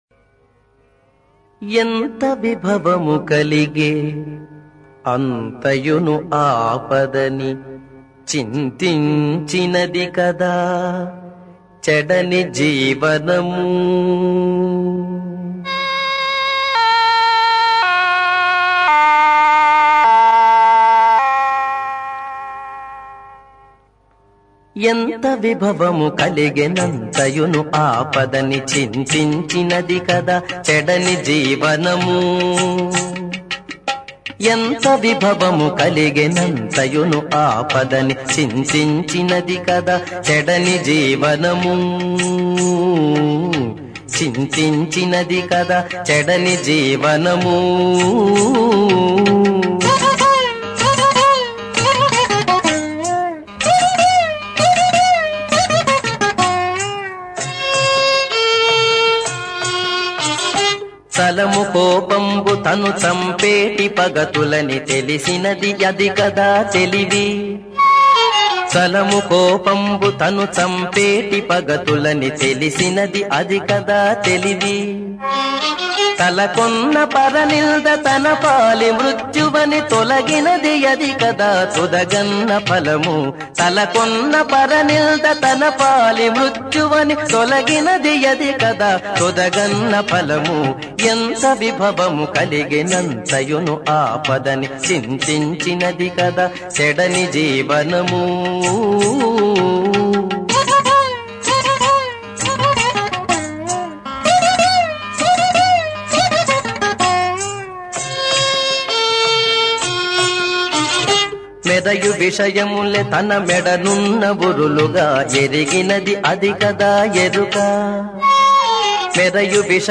సంగీతం
సంకీర్తన